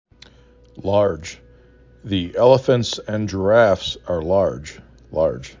large 4 /l/ /o/ /r/ /j/ Frequency: 720
5 Letters, 1 Syllable
lo r j